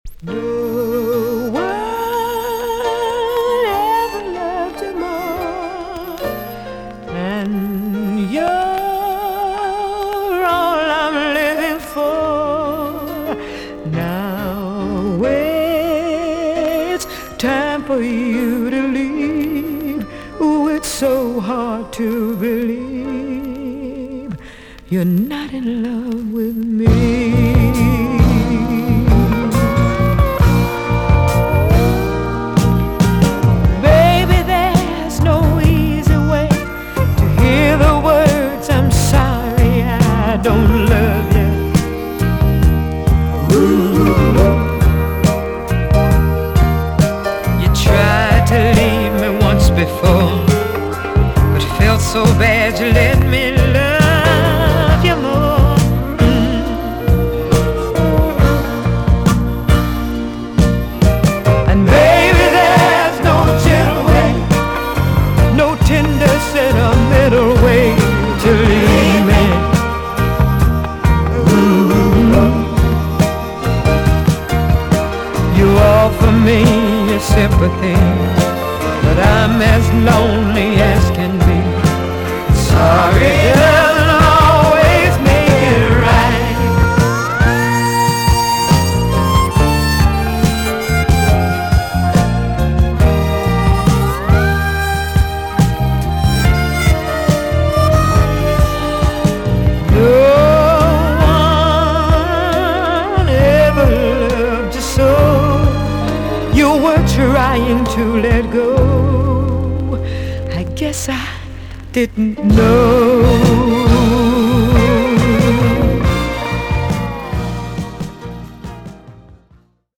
EX- 音はキレイです。
1977 , NICE JAMAICAN SOUL TUNE!!